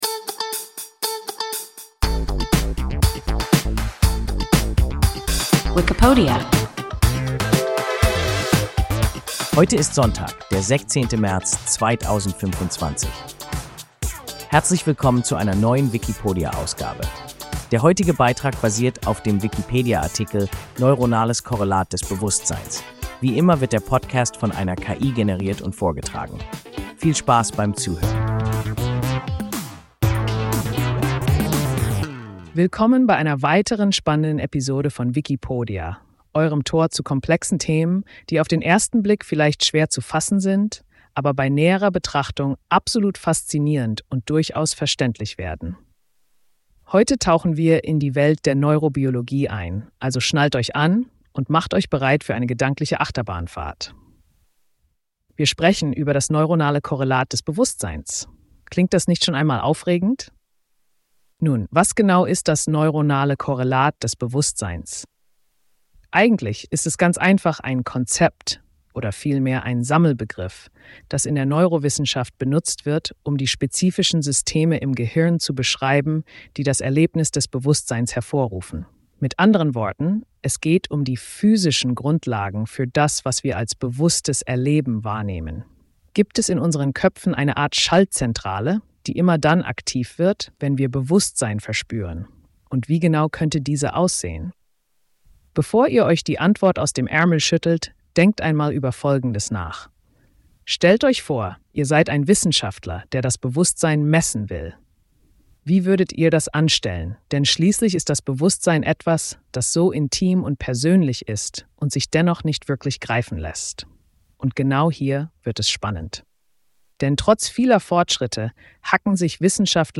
Neuronales Korrelat des Bewusstseins – WIKIPODIA – ein KI Podcast